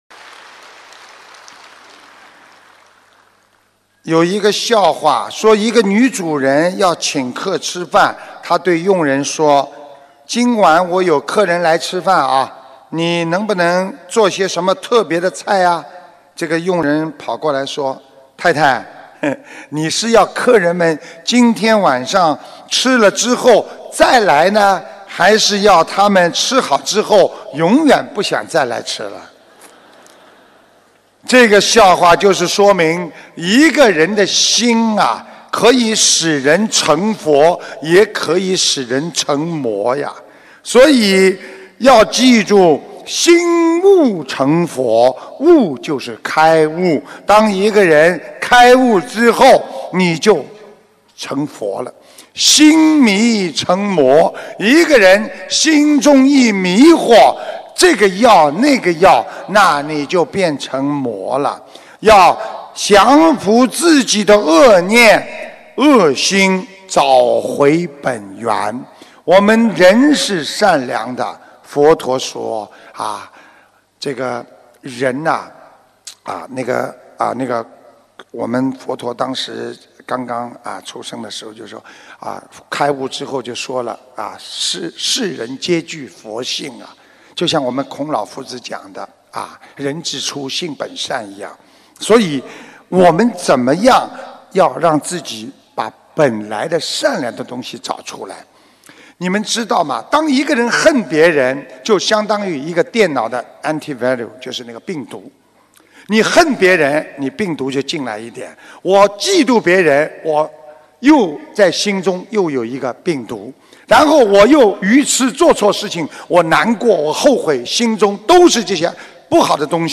音频：可爱的女佣·师父讲笑话